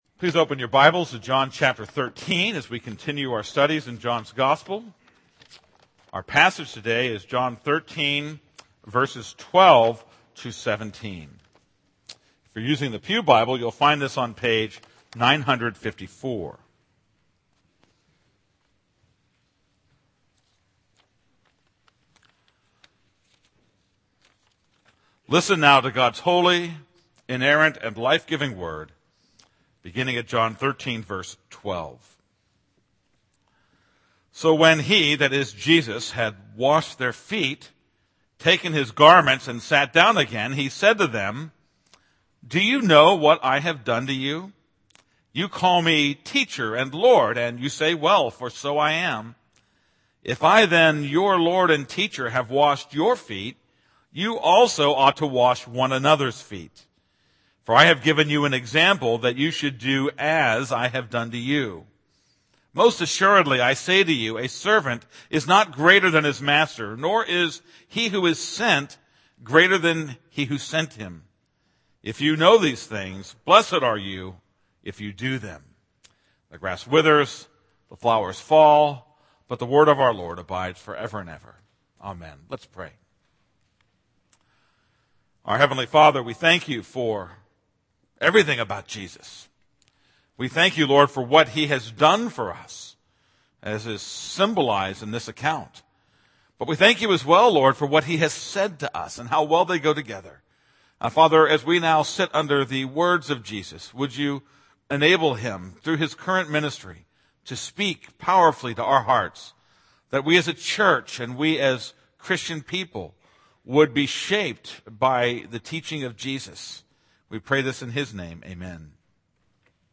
This is a sermon on John 13:12-17.